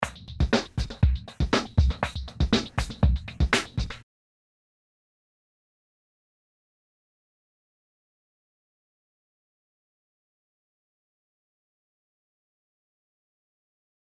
インポートしたままの状態のリズムパターンを再生した状態。